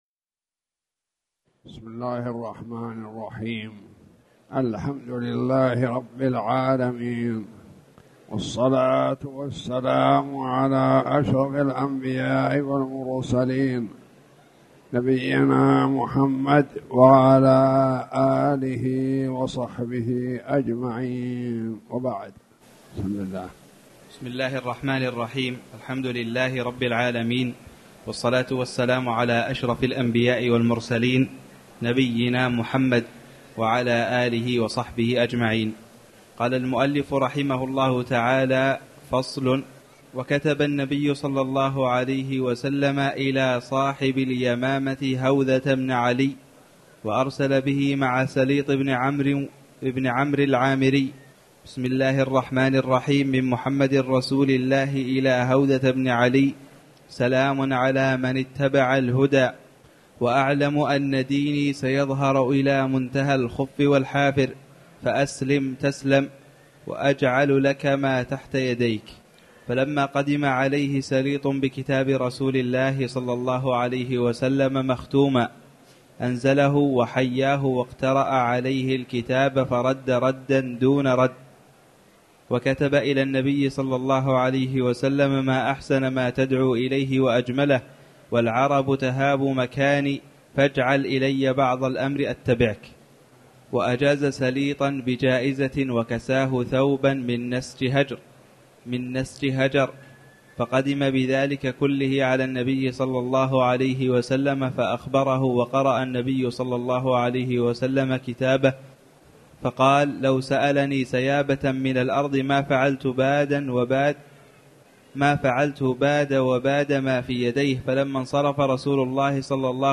تاريخ النشر ١٢ ذو القعدة ١٤٣٨ هـ المكان: المسجد الحرام الشيخ